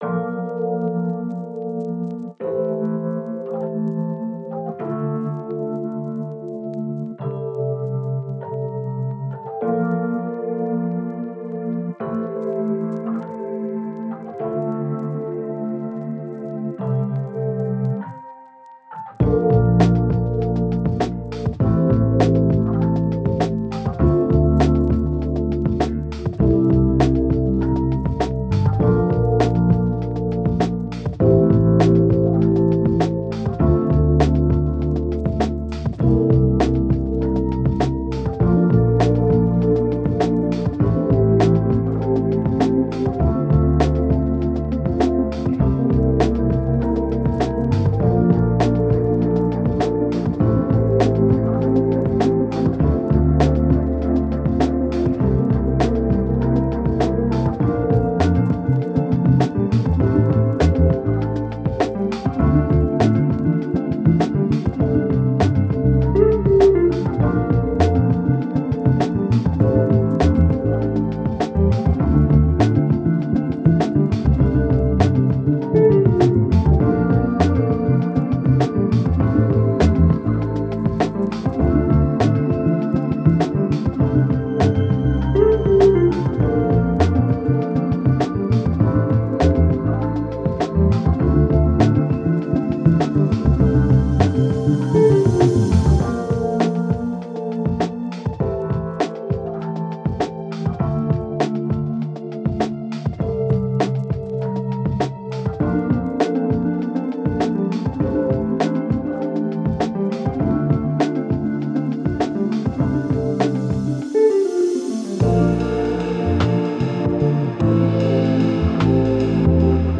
100 bpm – Magical Eminence